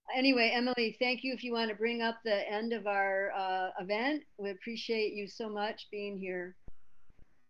(audio captured from zoon meeting)
07. emily saliers introduction (0:07)